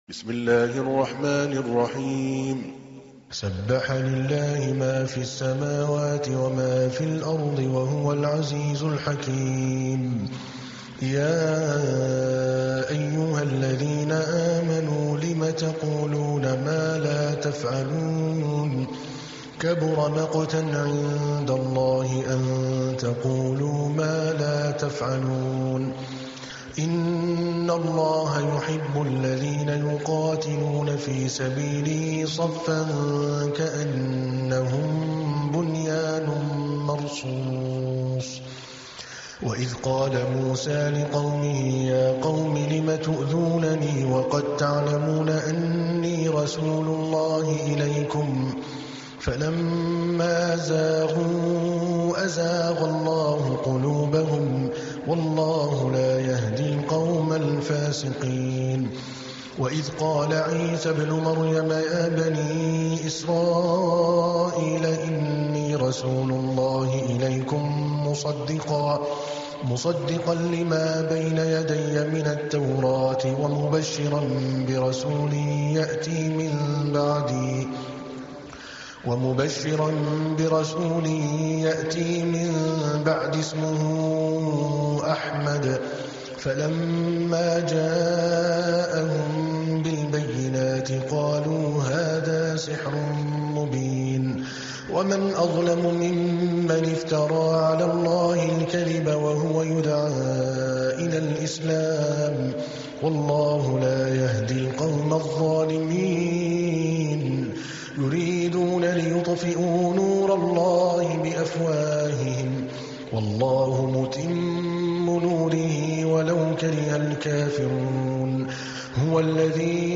تحميل : 61. سورة الصف / القارئ عادل الكلباني / القرآن الكريم / موقع يا حسين